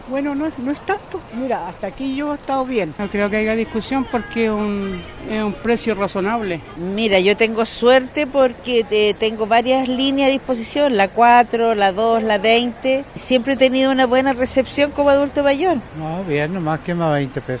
Algunos de los pasajeros mostraron conformidad y argumentaron que no tendrá mayor impacto para los usuarios.